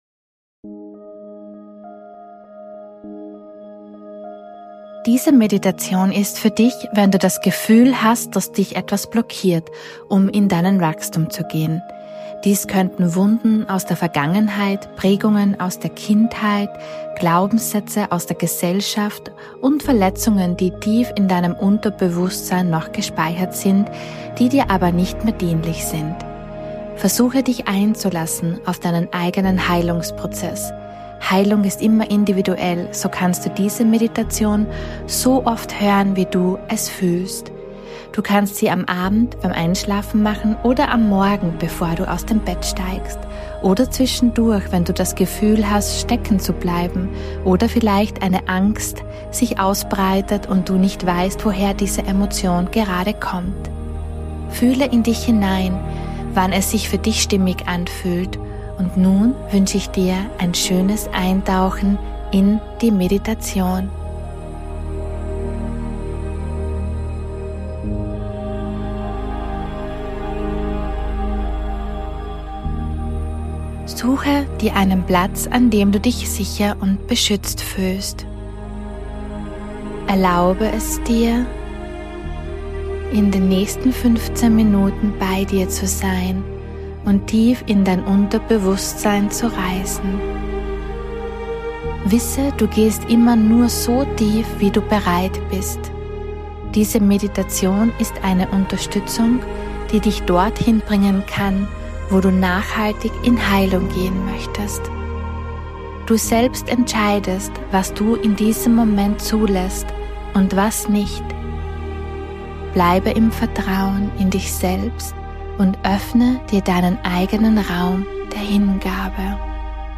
048 I Meditation: Heile die Wunden aus deiner Vergangenheit ~ Intu Soul - Der Podcast